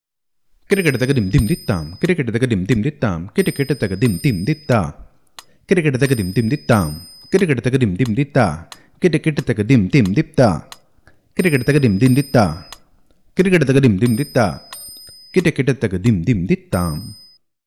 This is a mukthayam of 24 beats, which is a combination of both chaturashra nade and trishra nade.
Konnakol